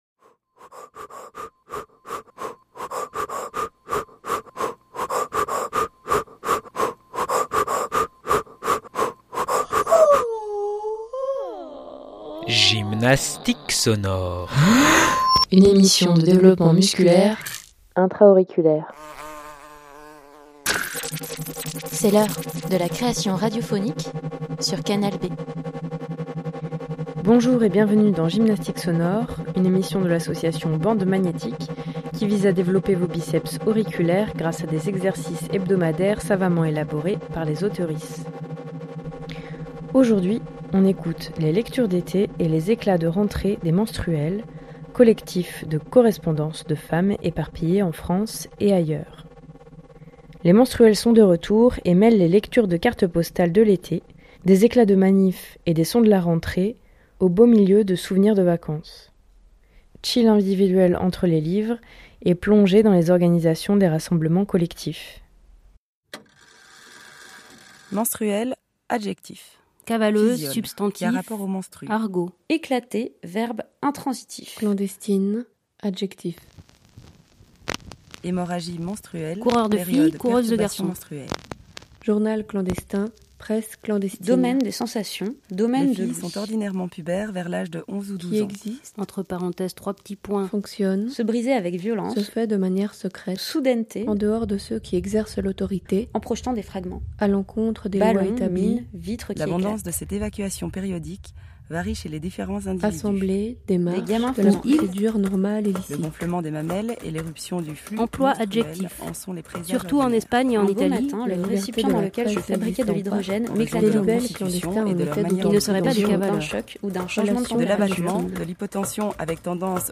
Les Menstruelles sont de retour et mêlent les lectures et cartes postales de l’été. Des éclats de manif et des sons de la rentrée, au beau milieu de souvenirs de vacances.